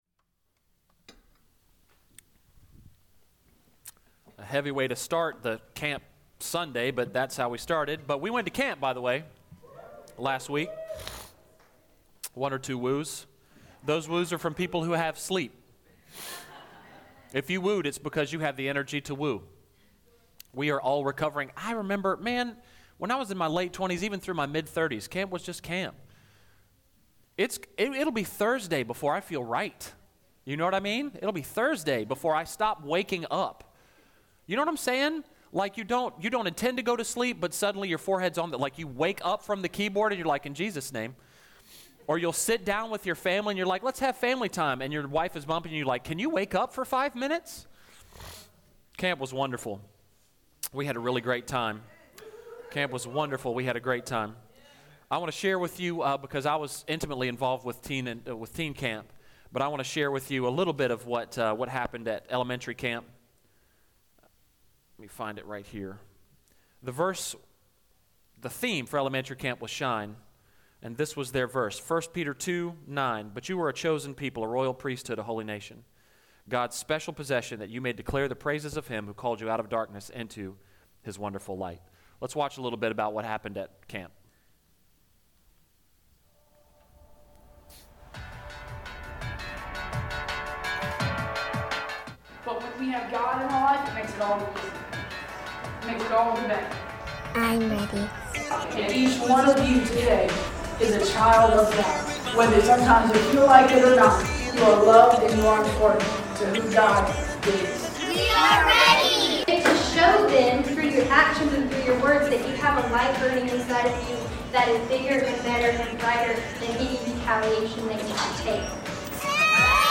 Maranatha Fellowship Church – Recap: Summer Camp 2019